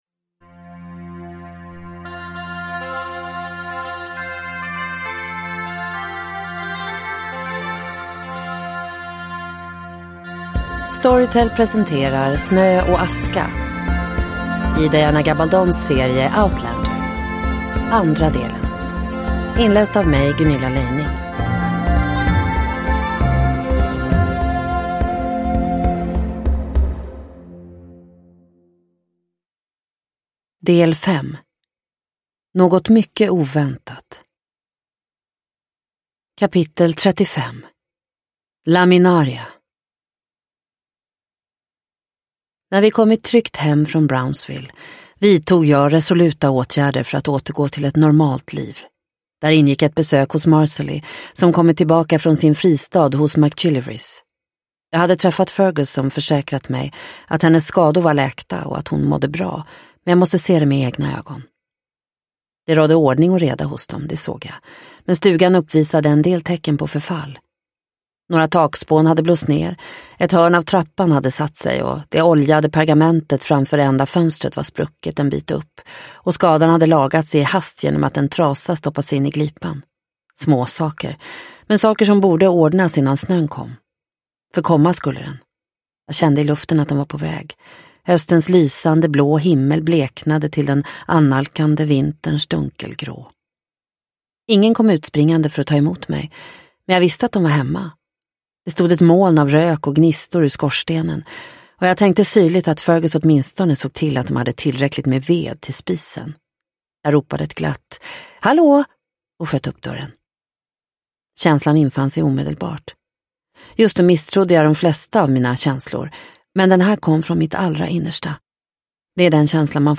Snö och aska - del 2 – Ljudbok – Laddas ner
Uppläsare: